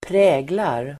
Uttal: [²pr'ä:glar]